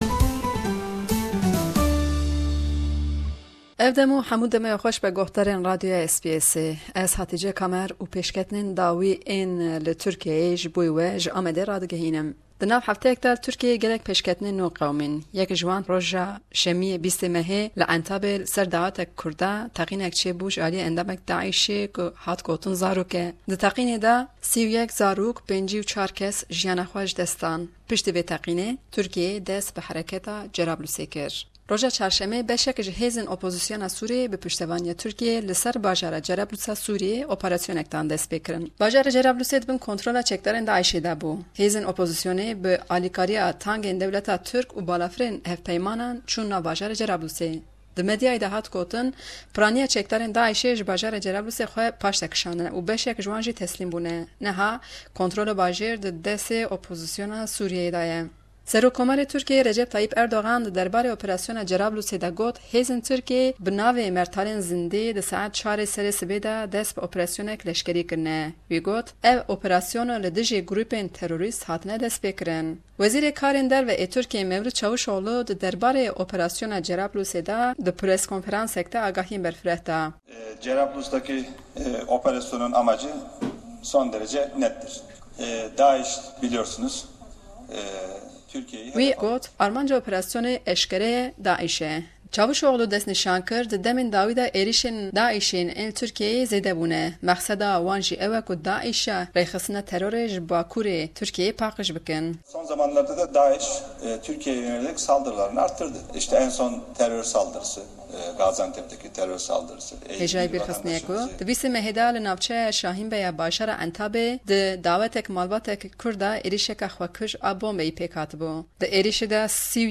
Raporta ji Diyarbekir